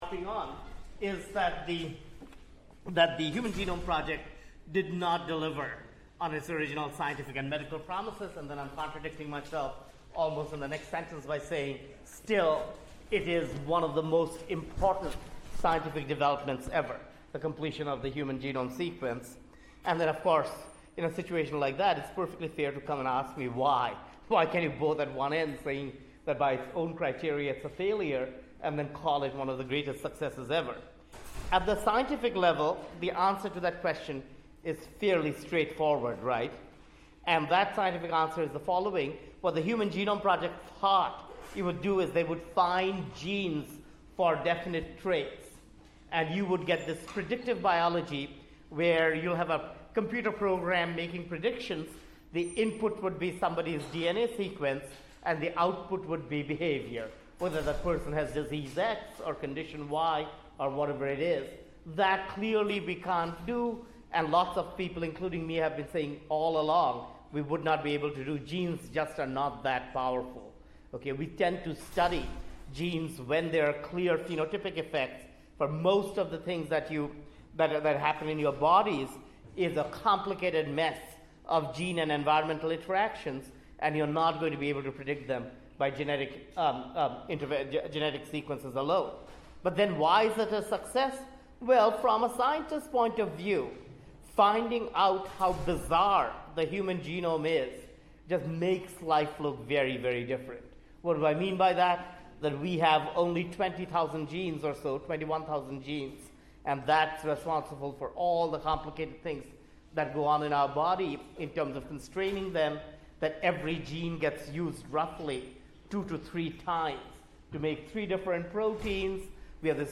Eliminating Genetic Diseases Lecture Notes